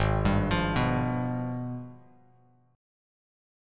AbmM13 chord